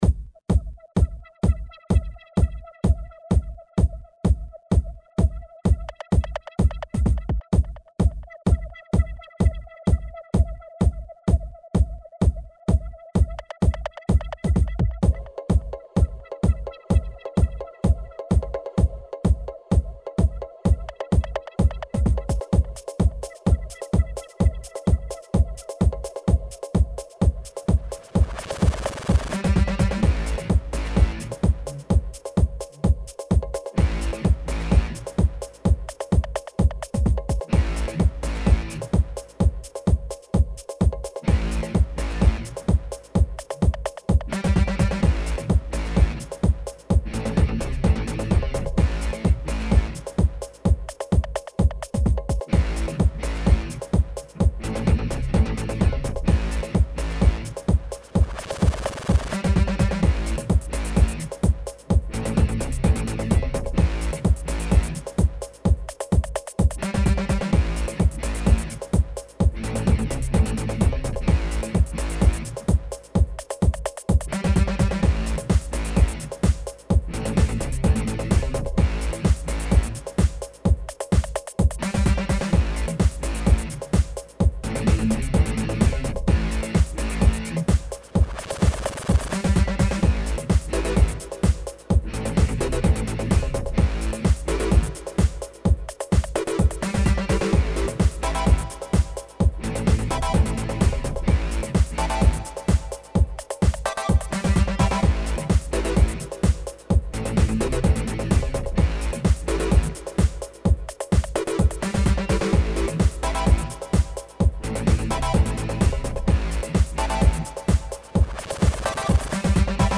Indie Dance - 5:28